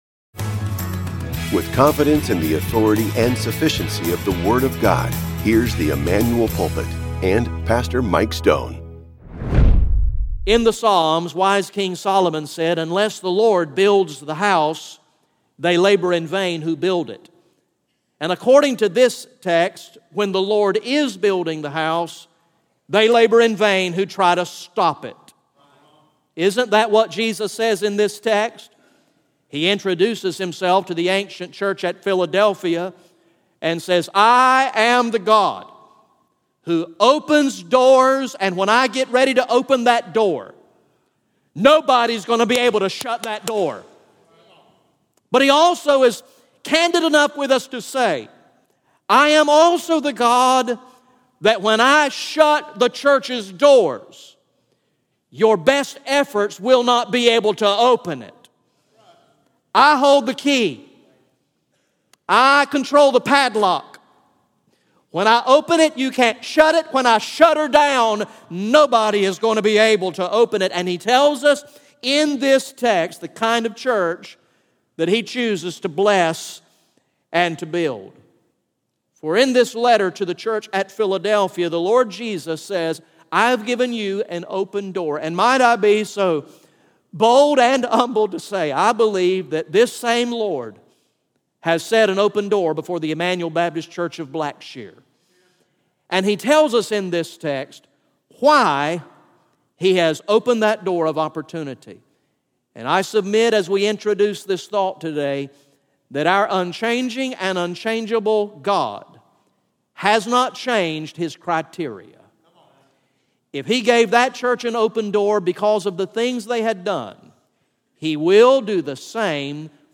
GA Message #01 from the sermon series entitled